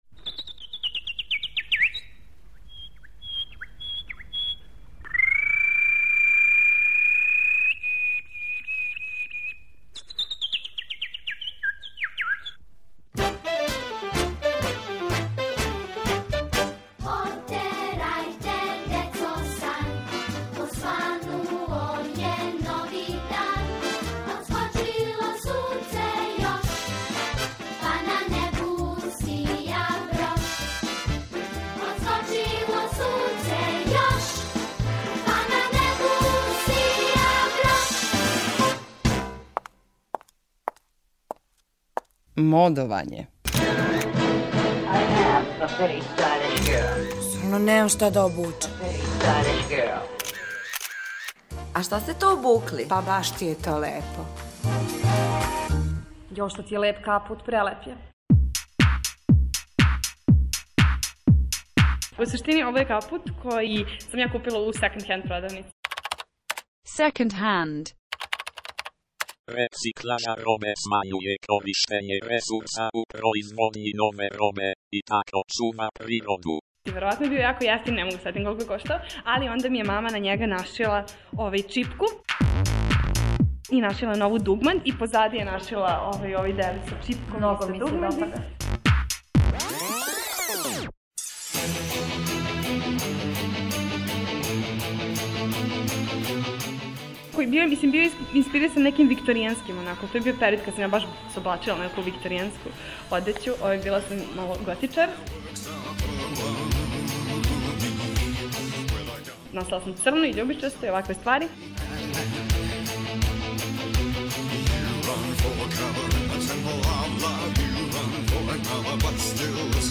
У серијалу МОДОВАЊЕ случајни, лепо одевени пролазници, описују своје одевне комбинације.